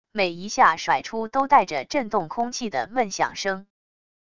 每一下甩出都带着震动空气的闷响声wav音频